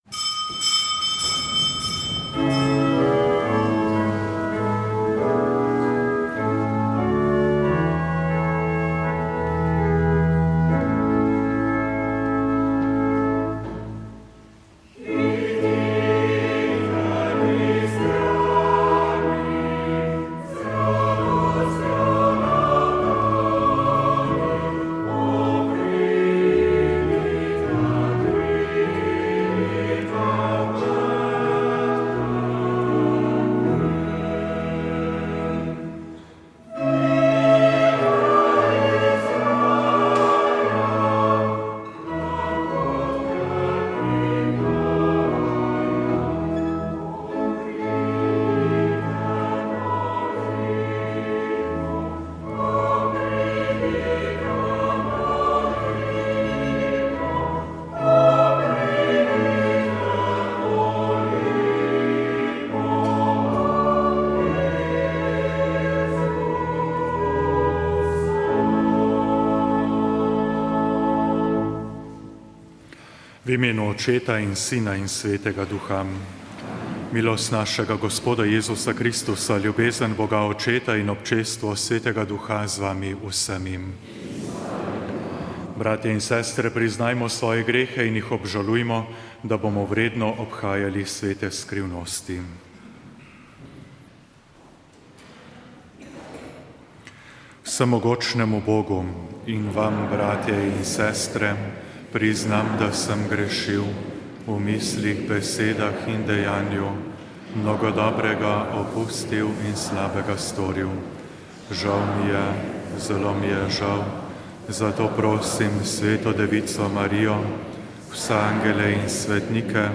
Sv. maša iz stolne cerkve sv. Janeza Krstnika v Mariboru 2. 9.